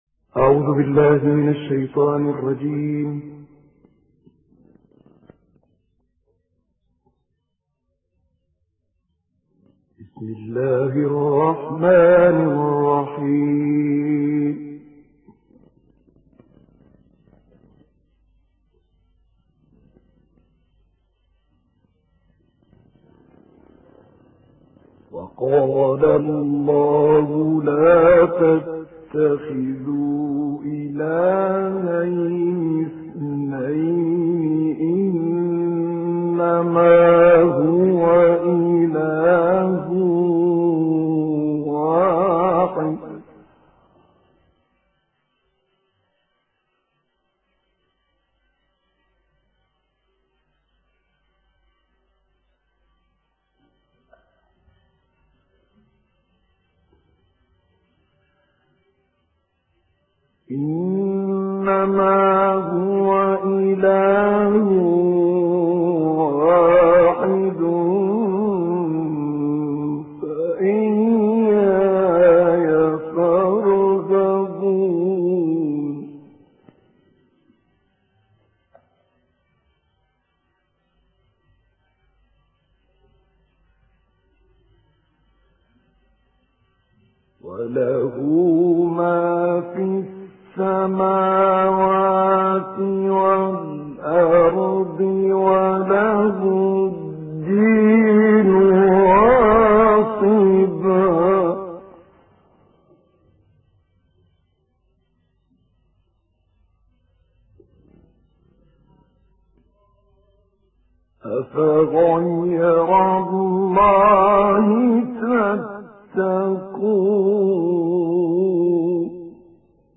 تلاوت سوره نحل از «طه الفشنی»
گروه شبکه اجتماعی: تلاوت آیاتی از سوره نحل با صوت طه الفشنی را می‌شنوید.